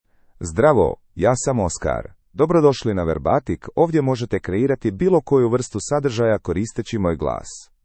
OscarMale Croatian AI voice
Oscar is a male AI voice for Croatian (Croatia).
Voice sample
Listen to Oscar's male Croatian voice.
Male